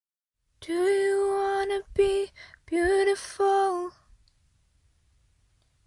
声乐循环的声音 " Feeling4
标签： 声乐 女子 女孩 唱歌
声道立体声